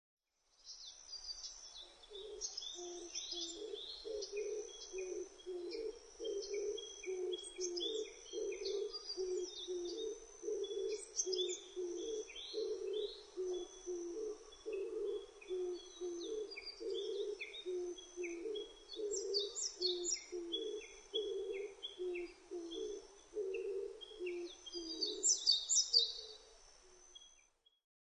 キジバト　Streptoperia orientalisハト科
日光市稲荷川中流　alt=730m  HiFi --------------
MPEG Audio Layer3 FILE  Rec.: EDIROL R-09
Mic.: built-in Mic.
他の自然音：　 ウグイス・ホオジロ・シジュウカラ